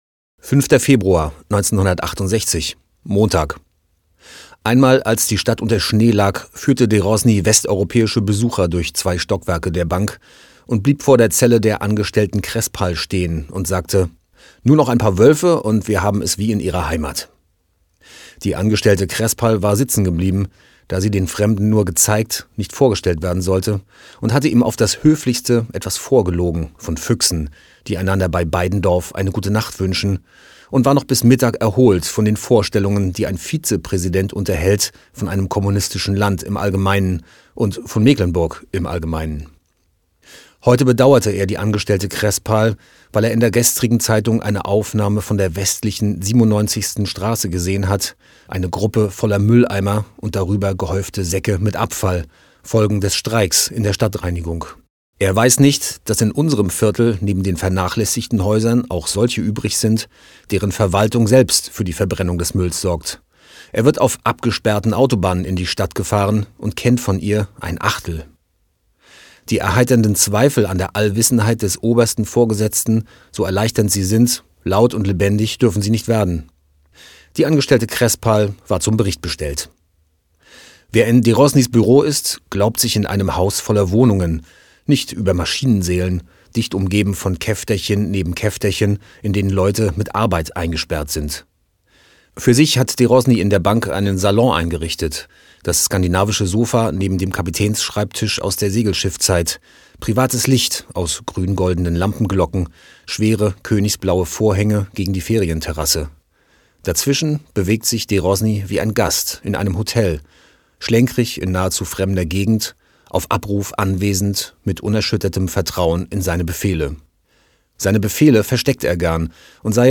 Eine Stadt liest Uwe Johnsons Jahrestage - 5.